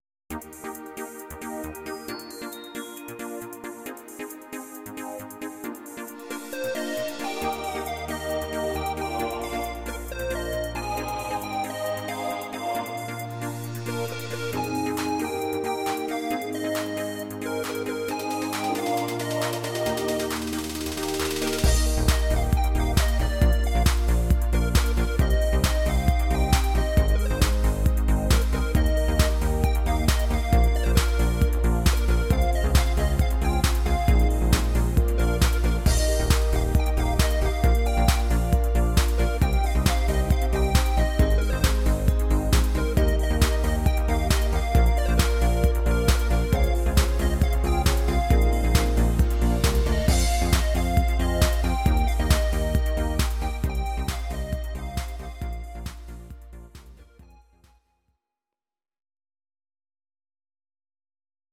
Audio Recordings based on Midi-files
German, 1970s